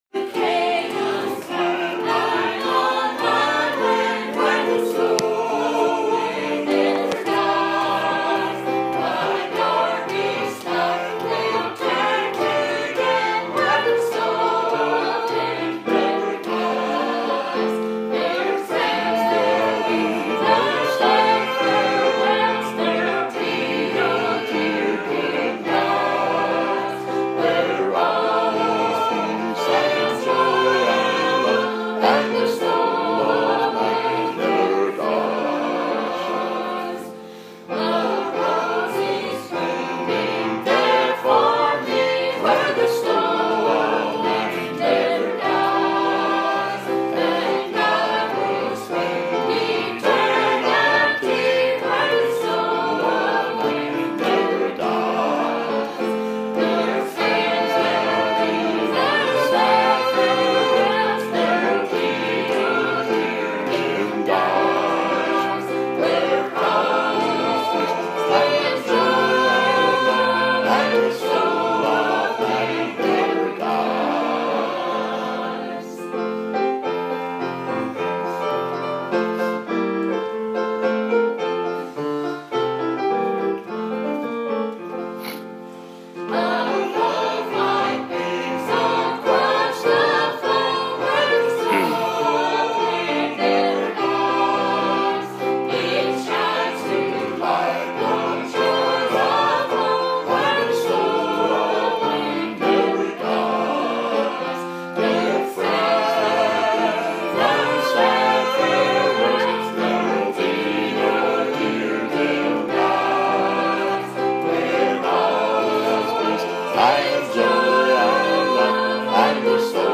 Preached at Riverview the morning of January 22, 2017